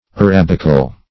Arabical \A*rab"ic*al\, a. Relating to Arabia; Arabic.